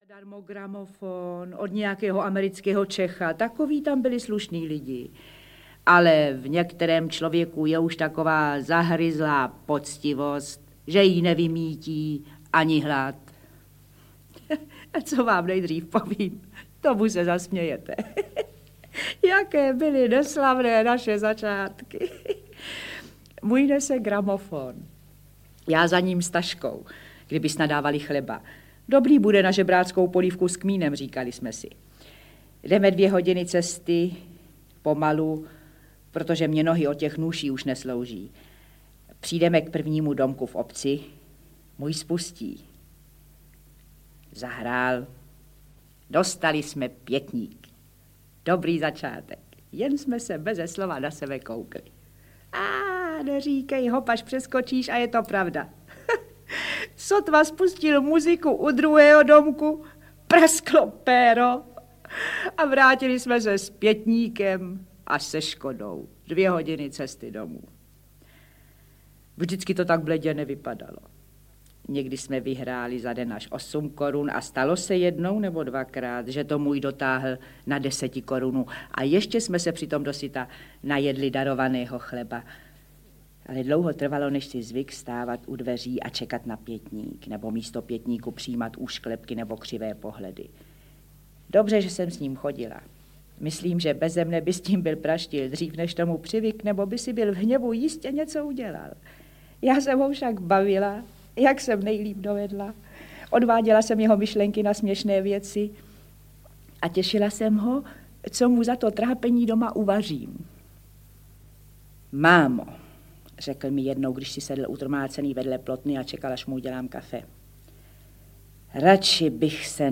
Česká umělecká próza audiokniha
Ukázka z knihy